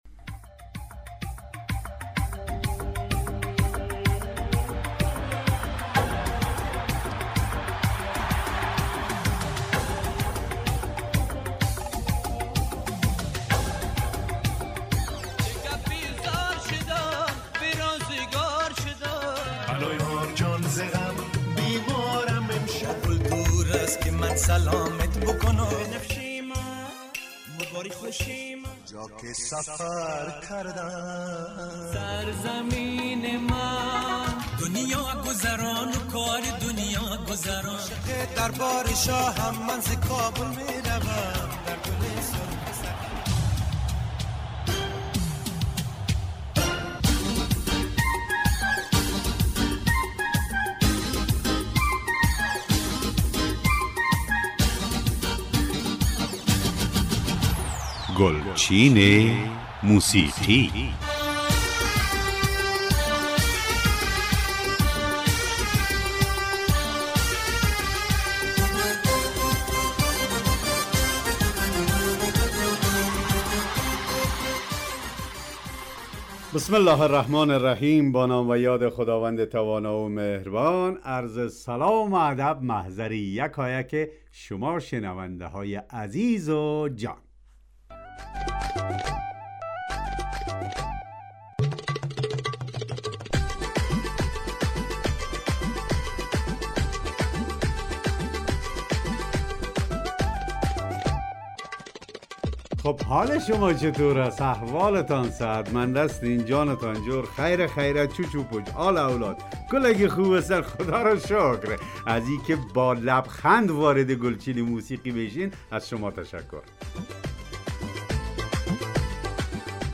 ترانه های درخواستی